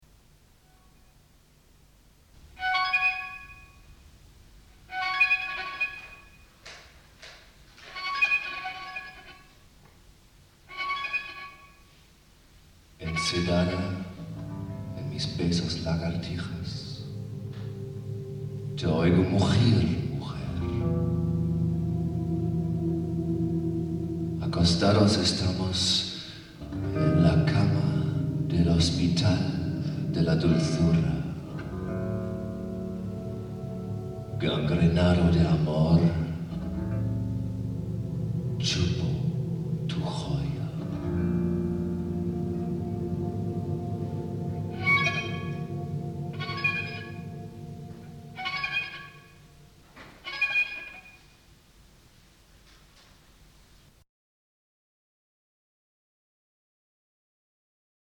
en directo 1995 Bajo electrico